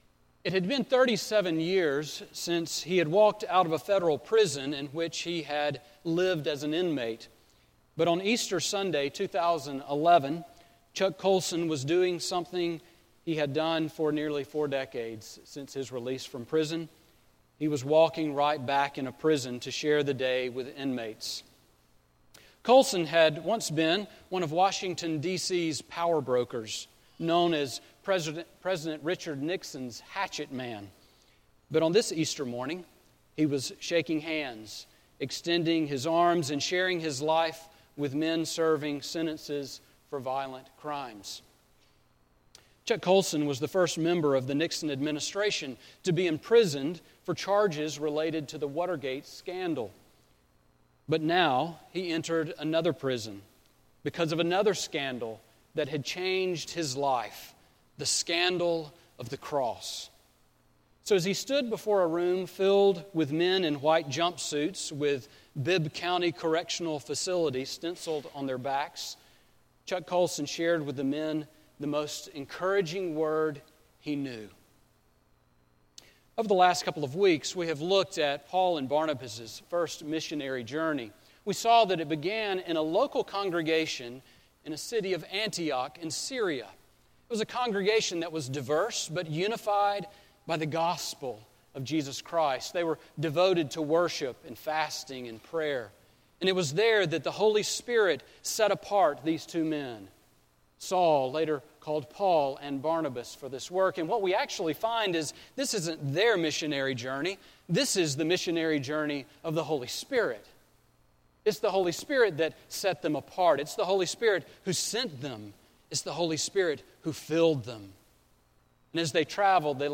Sermon on Acts 13:15, 26-43 from April 5